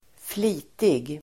Uttal: [²fl'i:tig]